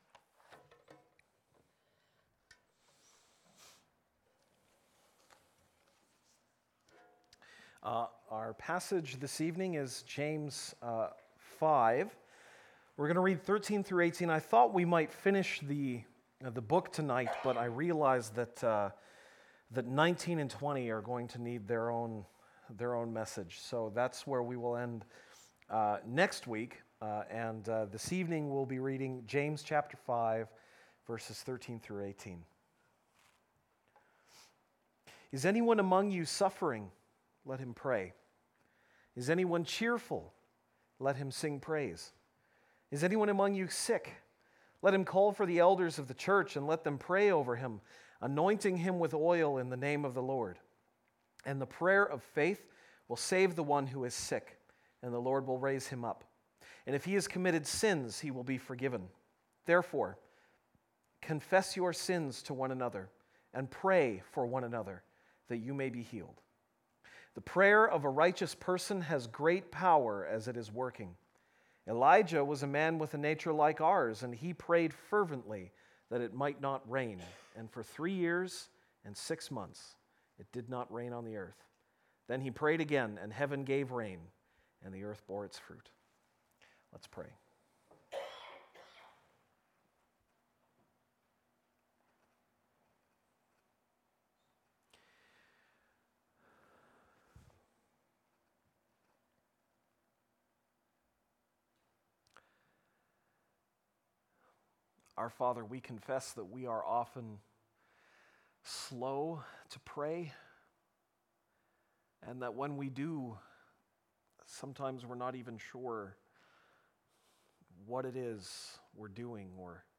Series: Archived Sermons
September 18, 2016 (Sunday Evening)